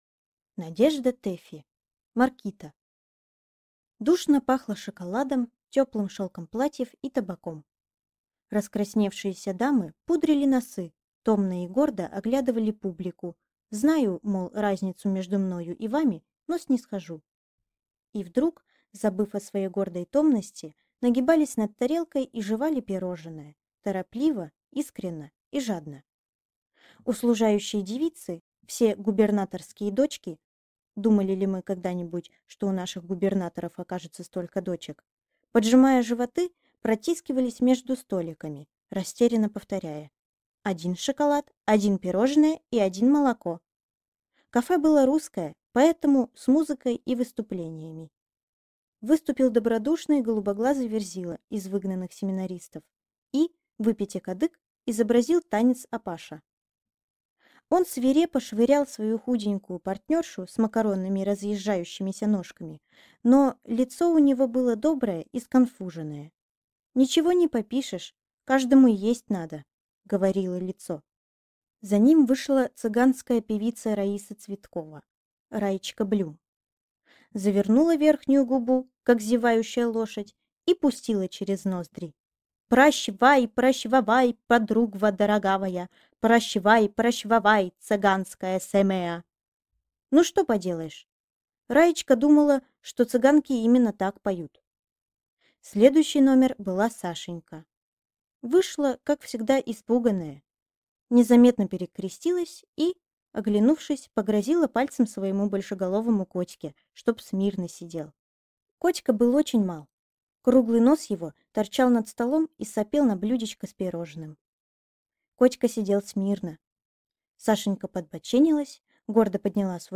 Аудиокнига Маркита | Библиотека аудиокниг
Прослушать и бесплатно скачать фрагмент аудиокниги